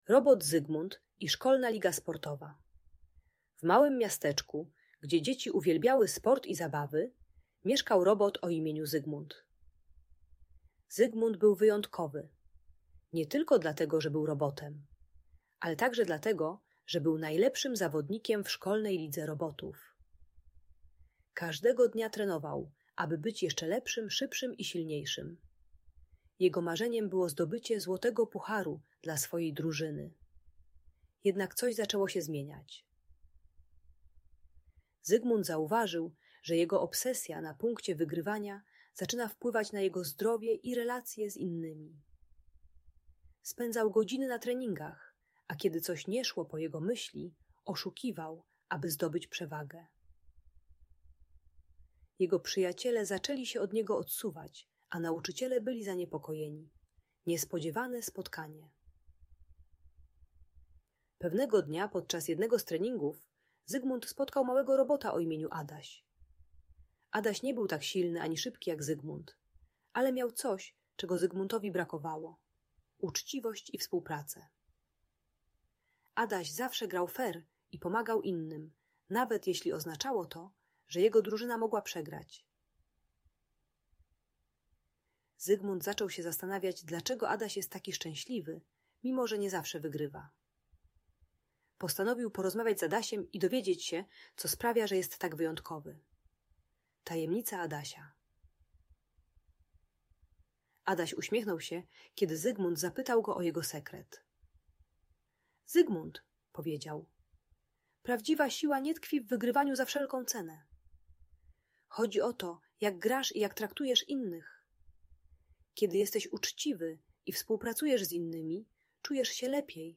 Robot Zygmunt i Szkolna Liga Sportowa - historia o uczciwości - Audiobajka